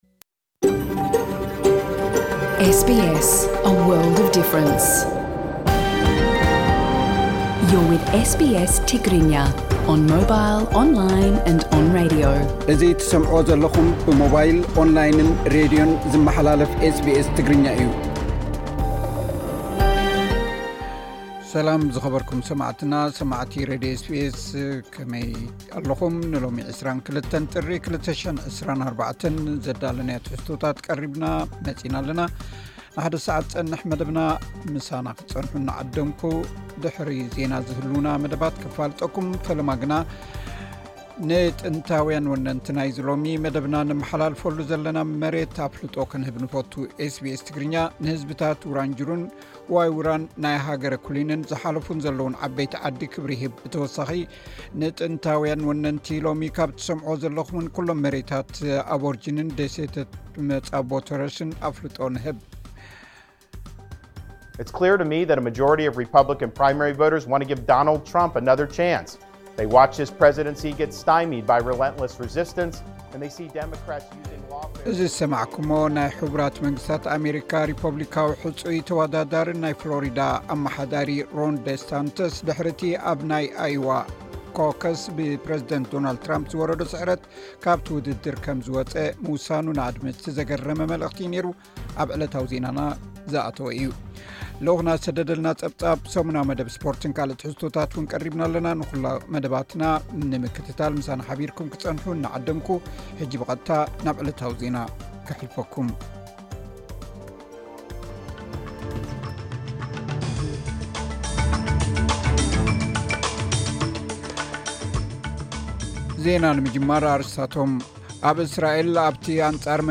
ቀጥታ ምሉእ ትሕዝቶ ኤስ ቢ ኤስ ትግርኛ (22 ጥሪ 2024)